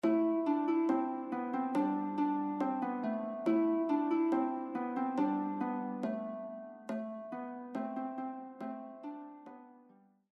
“Ai vist lo lop” or “I Saw the Wolf” is a traditional song sung in the Occitan language dating back to the 13th century.